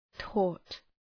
Προφορά
{tɔ:t}